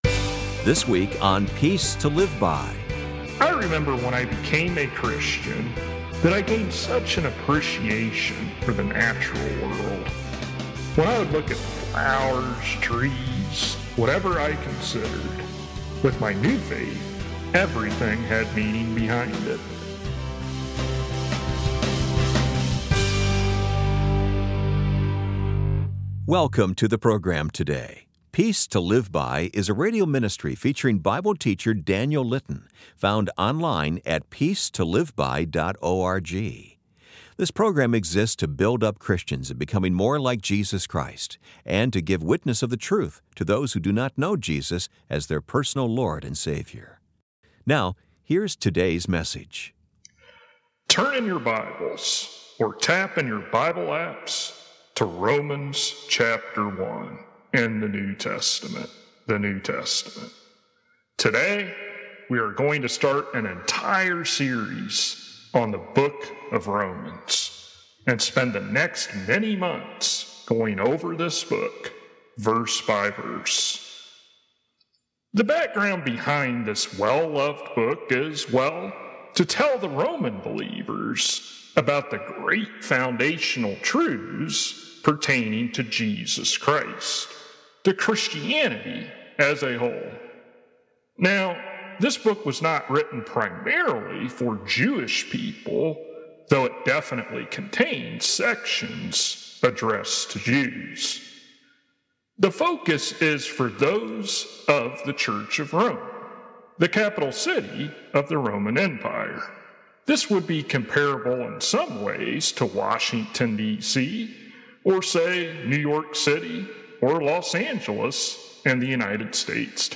[Transcript may not match broadcasted sermon word for word, and contains extra material that was cut from the broadcast due to time constraints] Today’s message will be our final in going through Romans chapter 8.